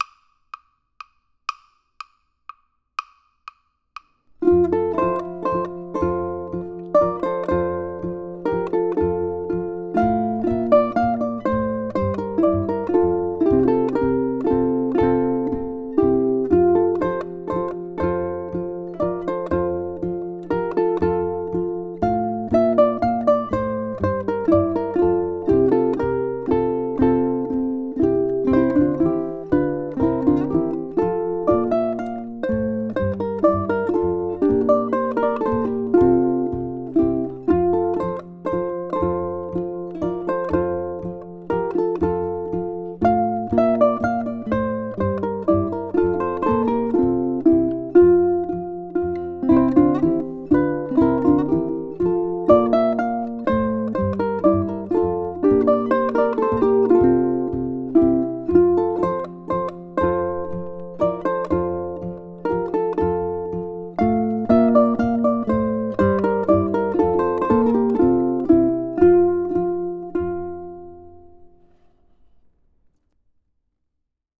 Our ensemble is a minuet, a stately ballroom dance in triple meter.
Minuet is a trio and thus has separate parts: ʻukulele 1, ʻukulele 2, and ʻukulele 3.
Aim for a gentle allegro of about 120 BPM.
ʻukulele